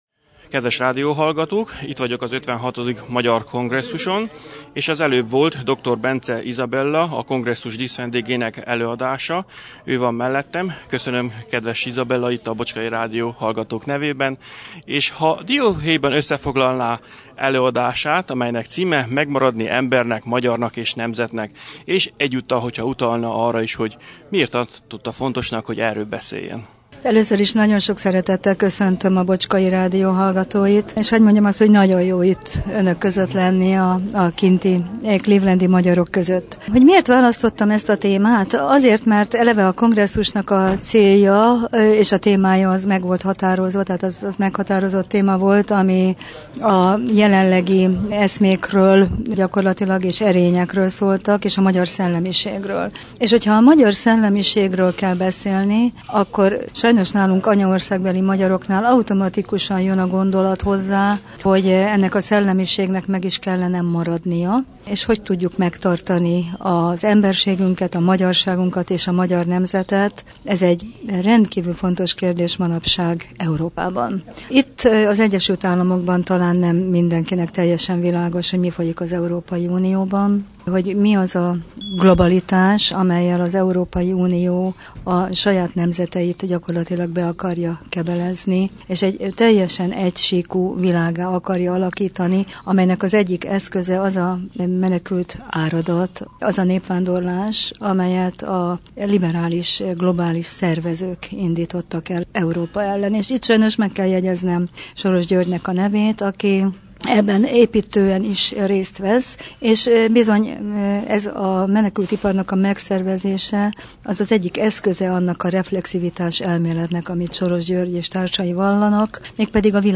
A kongresszus során több interjút is készítettünk